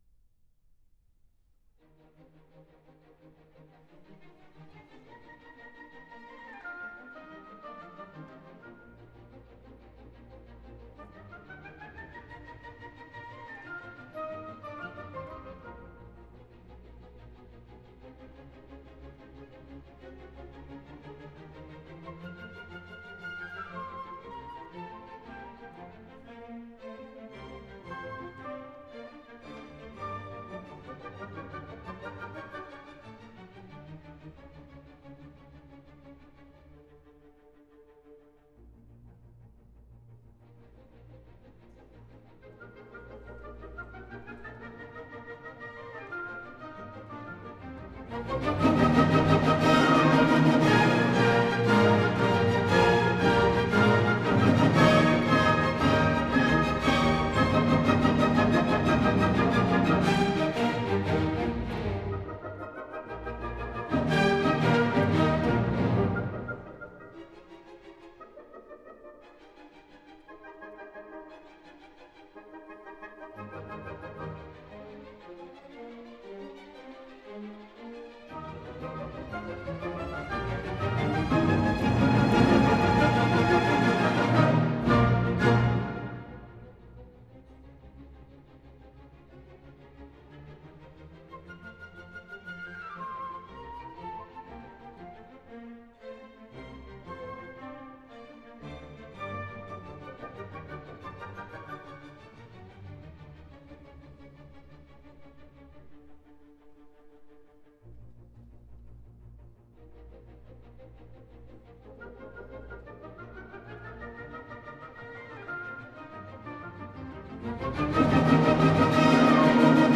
Scherzo. Allegro vivace - Trio.mp3 — Laurea Triennale in Scienze e tecnologie della comunicazione
3-scherzo-allegro-vivace-trio.mp3